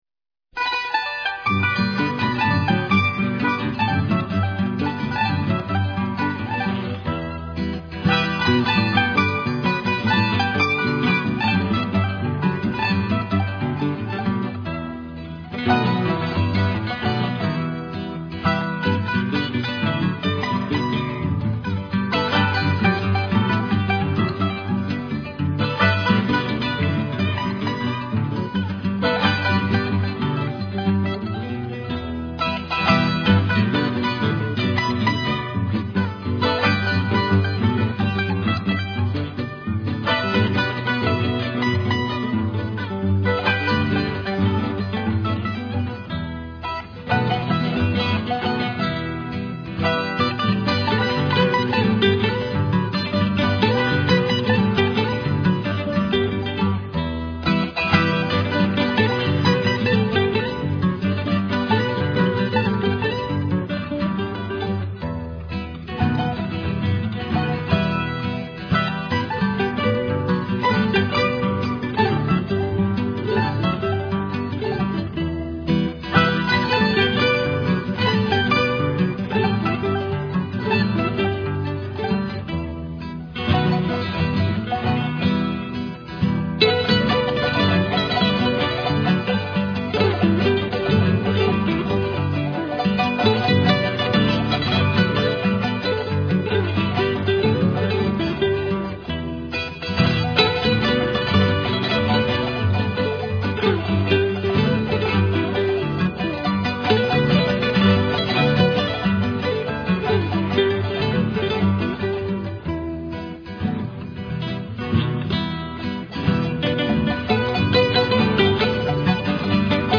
Khirkhinchu (Charango)
parlanteRecuerdos de mi Tierra con copyright popular e interpretado por el Trío Yanahuara de Arequipa.